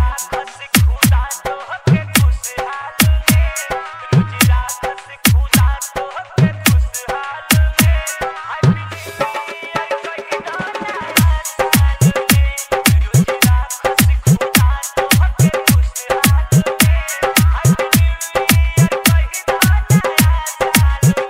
Bollywood Ringtones